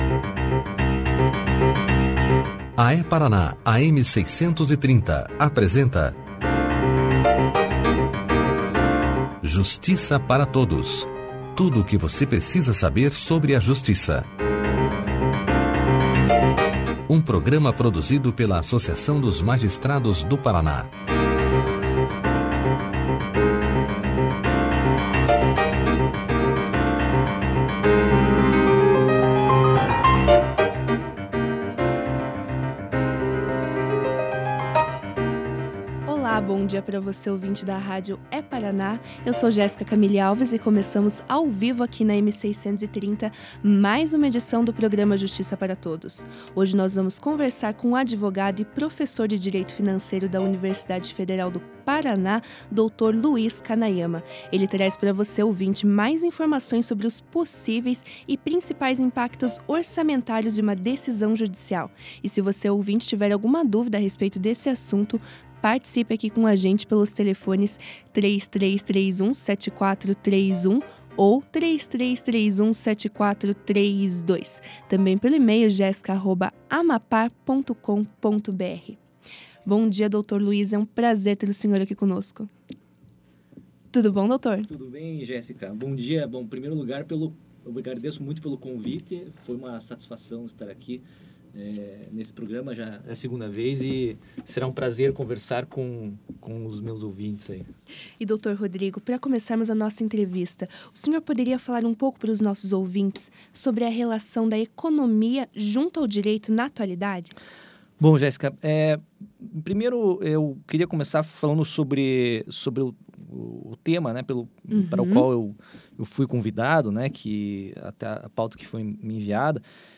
Clique aqui e ouça a entrevista do advogado e professor da UFPR